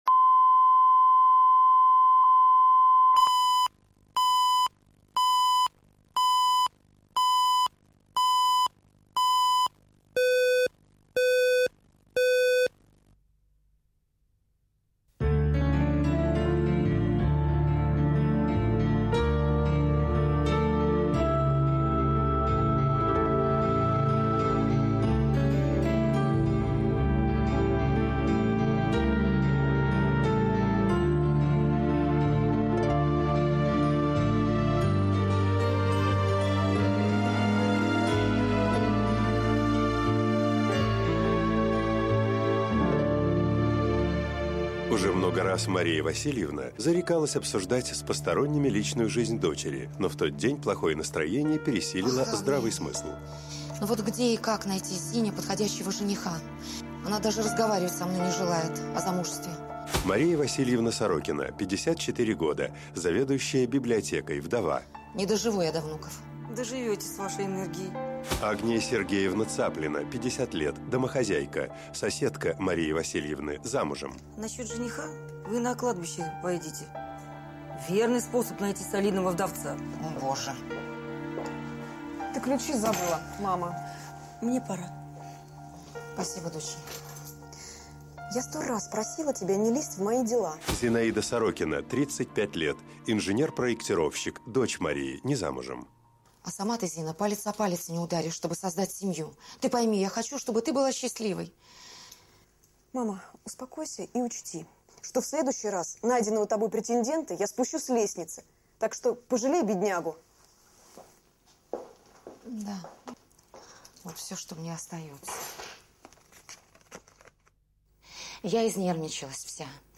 Аудиокнига Небольшая случайность | Библиотека аудиокниг